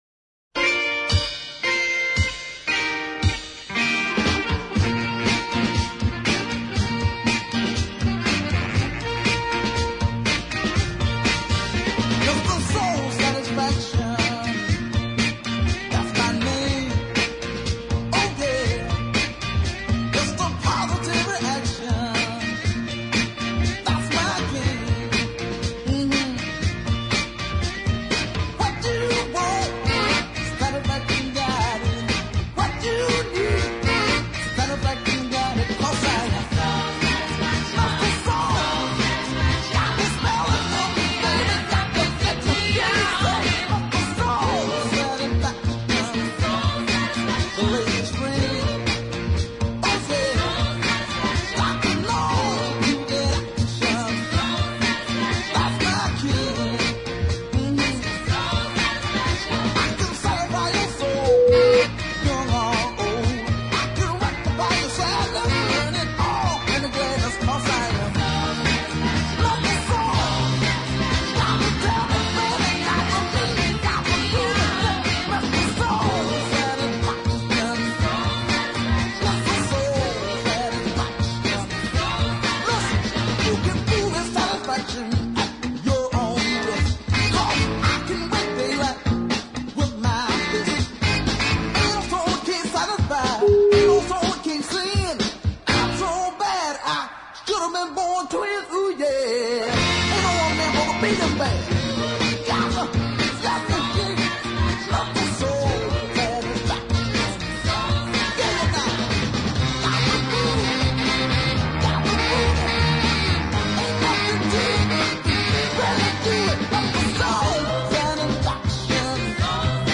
He is low down and dirty, as gritty as you could want.